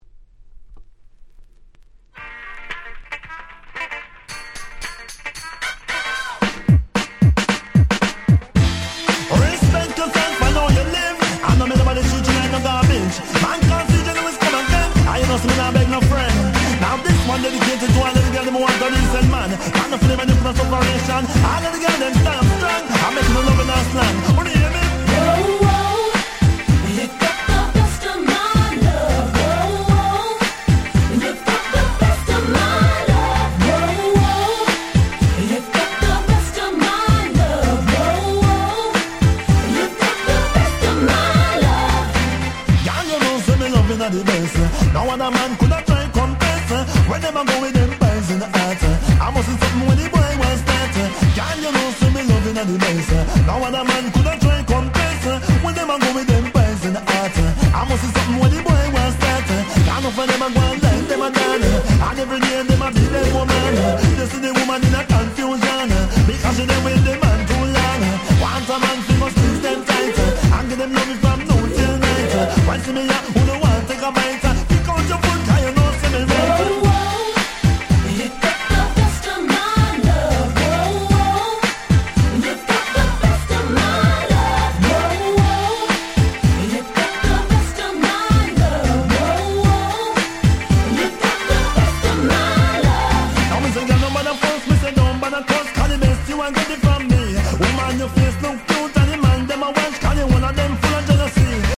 このコンピの特徴は「音が良い」事。
夏にぴったりなPop Reggaeヒット4曲収録で超お得！